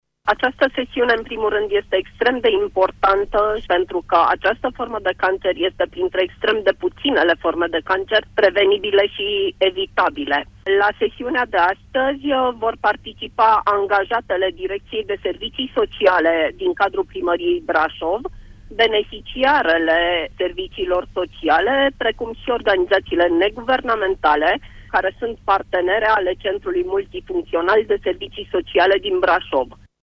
invitată la Pulsul zilei